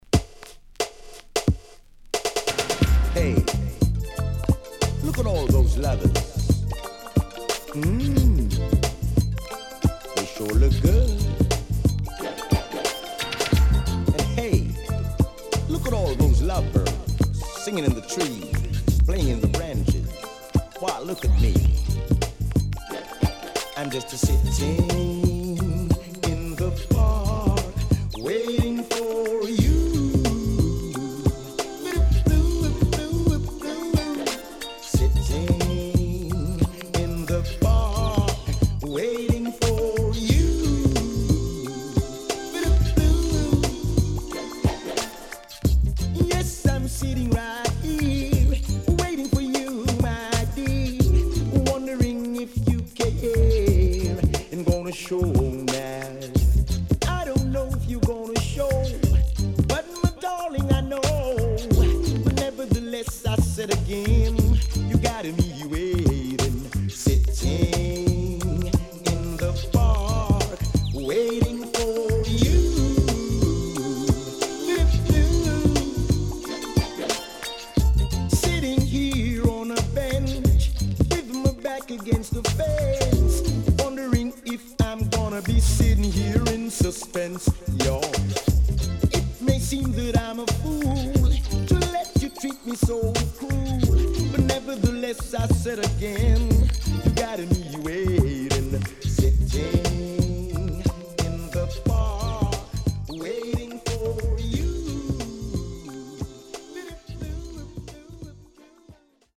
HOME > DANCEHALL
少しチリノイズ、プチノイズ入ります。